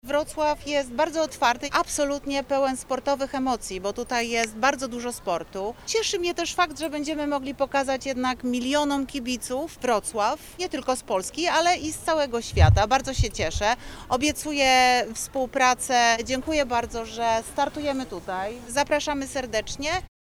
Tour de Pologne - konferencja prasowa
Drugi raz w historii wyścig startuje z Wrocławia, to ogromny zaszczyt, podkreśla Wiceprezydent Wrocławia Renata Granowska.